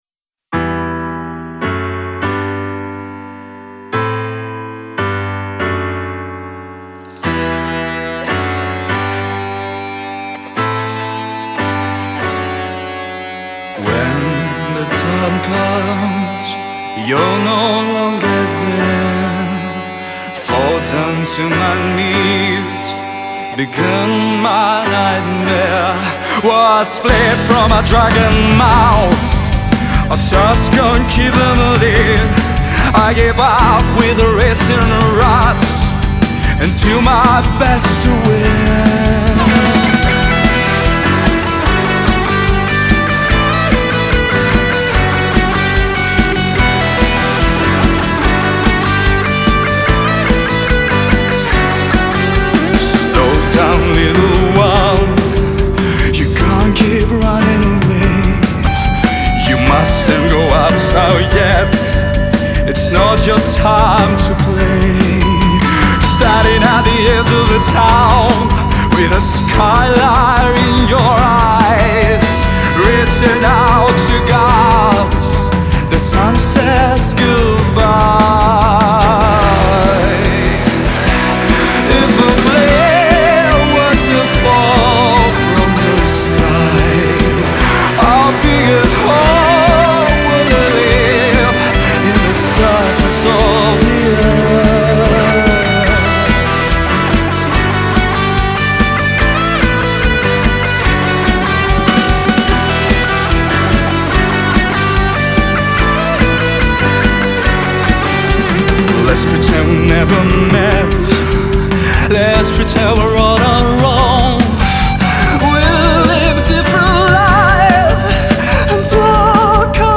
Rock live.